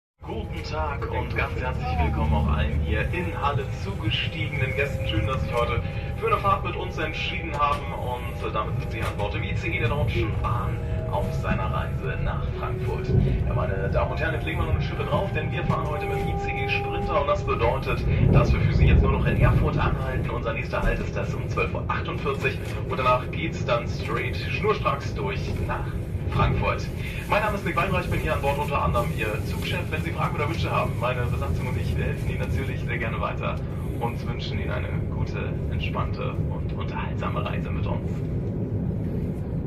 Wer einmal mit ihm im Zug gefahren Ist möchte nie wieder andere Durchsagen hören.